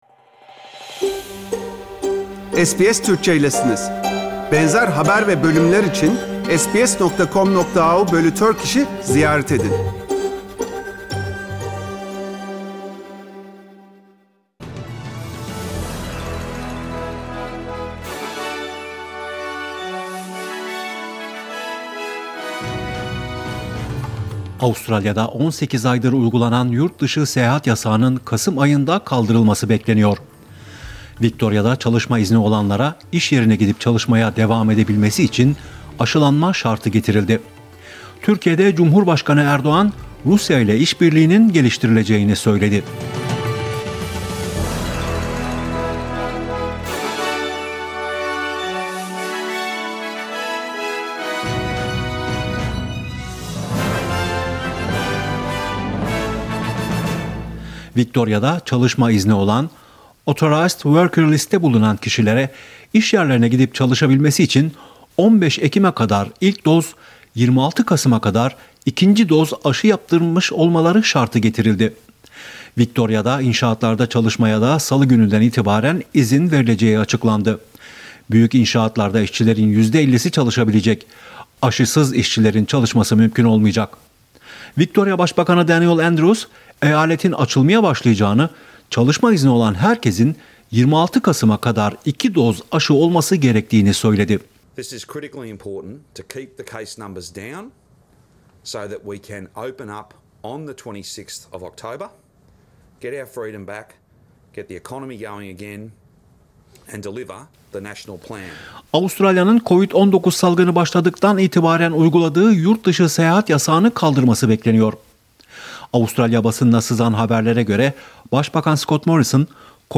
SBS Türkçe Haberler Source: SBS